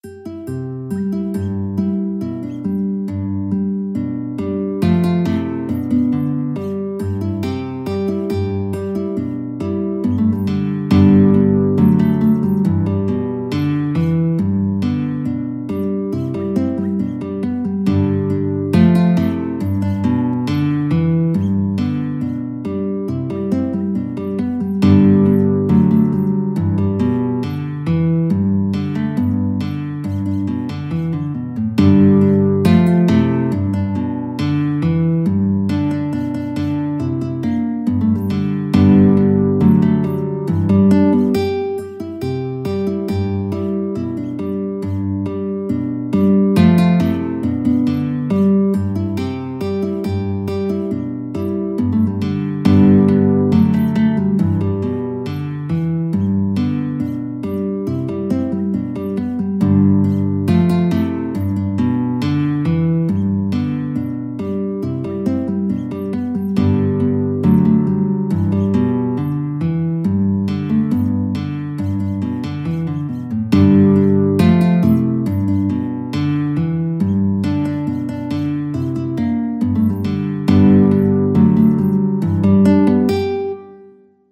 G major (Sounding Pitch) (View more G major Music for Guitar )
4/4 (View more 4/4 Music)
Guitar  (View more Intermediate Guitar Music)
Traditional (View more Traditional Guitar Music)